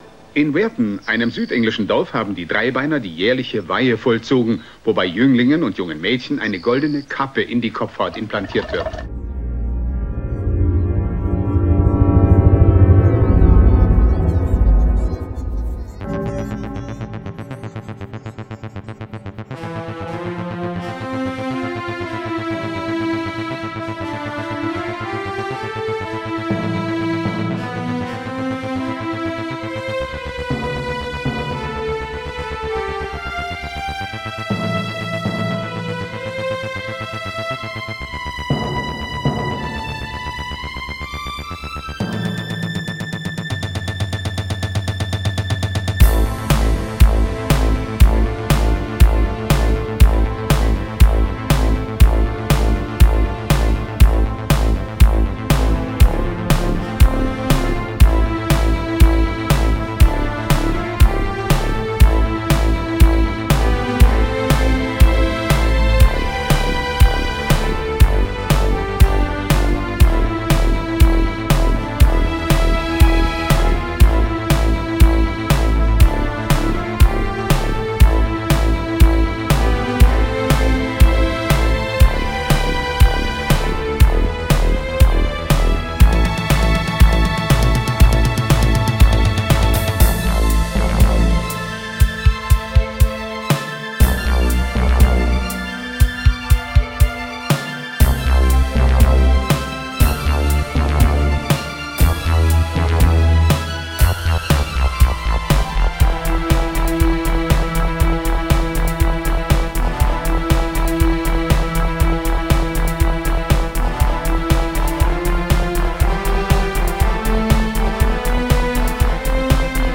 Theme-Neuabmischung